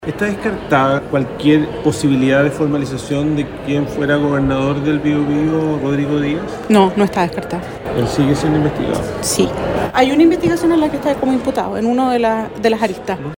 Las declaraciones las hizo en el marco de la Cuenta Pública 2024 sobre el trabajo del Ministerio Público en el Bío Bío, destacando, entre otros, los logros obtenidos en la investigación de los traspasos de dineros desde el Gobierno Regional a distintas fundaciones.
Estas fueron las respuestas de la fiscal Cartagena a las consultas realizadas por Radio Bío Bío al respecto.